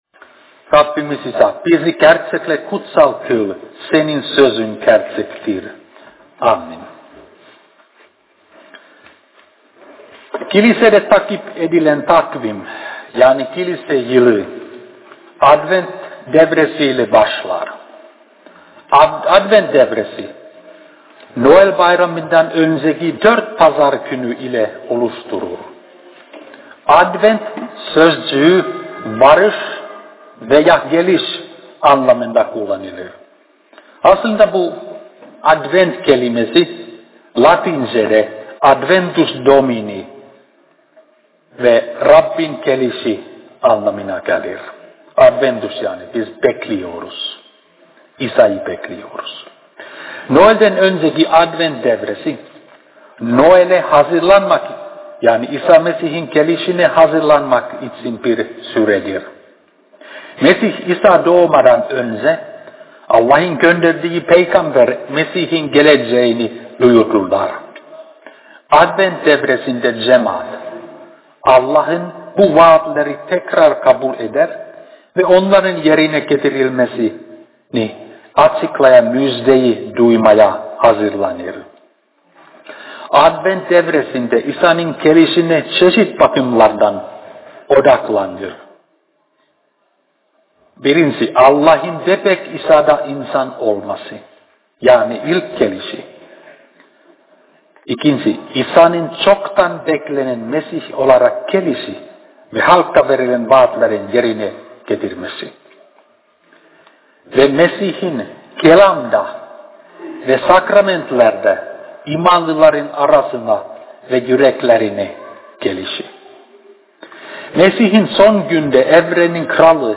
2010 yılı: Markos Kitabından Vaazlar